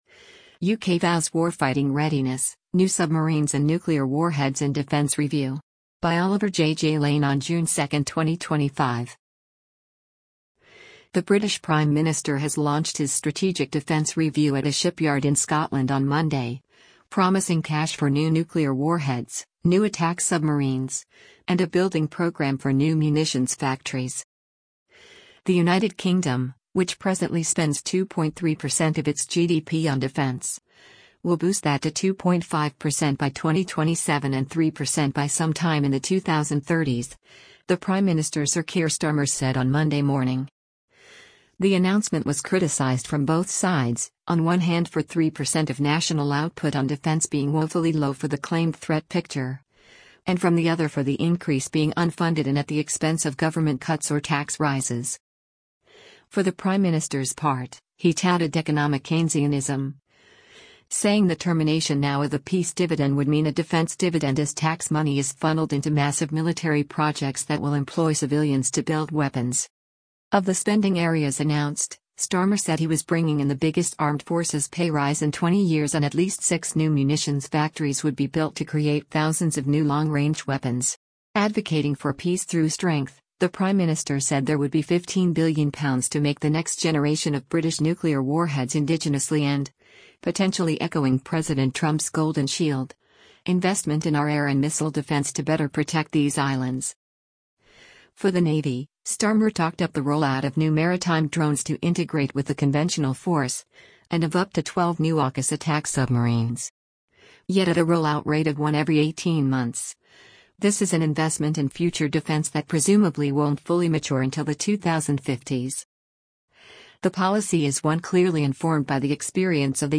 GLASGOW, SCOTLAND - JUNE 2: Britain's Prime Minister Keir Starmer delivers a speech d